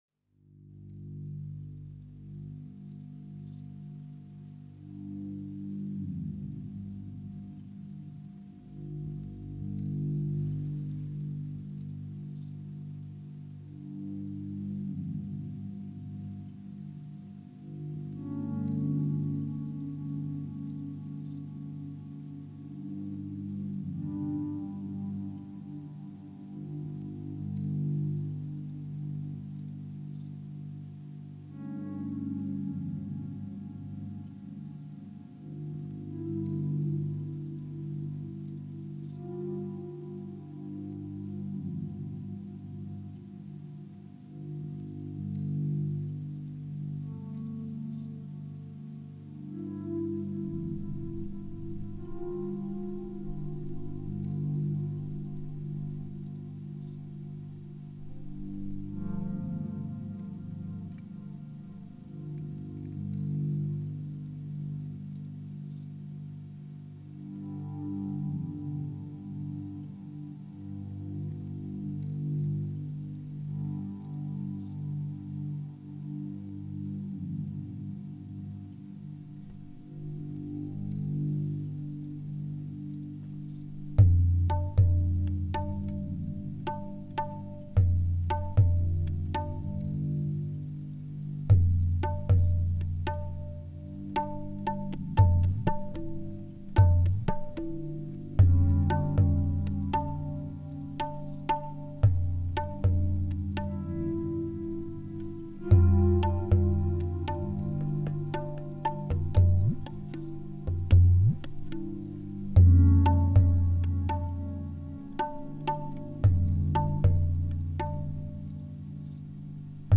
Live in Saranac Lake NY